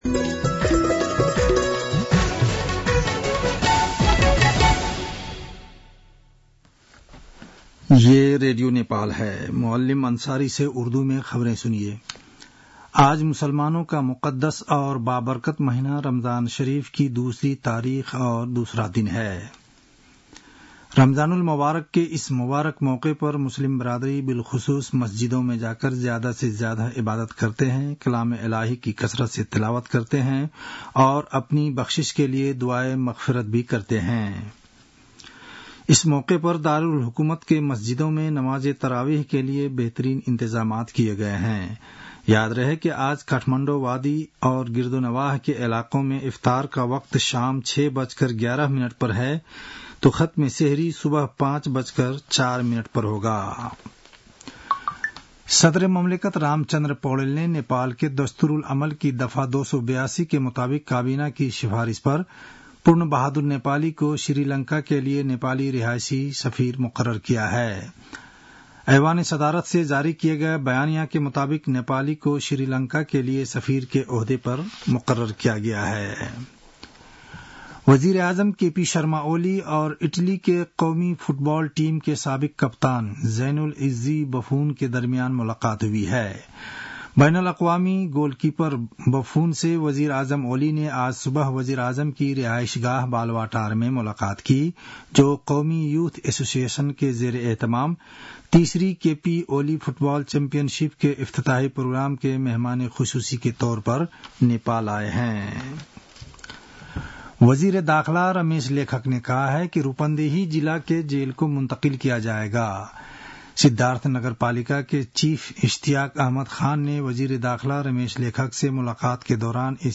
An online outlet of Nepal's national radio broadcaster
उर्दु भाषामा समाचार : २० फागुन , २०८१